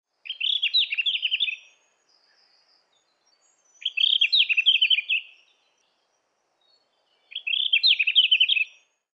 ルリビタキ｜日本の鳥百科｜サントリーの愛鳥活動
「日本の鳥百科」ルリビタキの紹介です（鳴き声あり）。オスは青いからだ、メスは尾だけわずかに青色です。